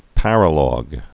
(părə-lôg, -lŏg)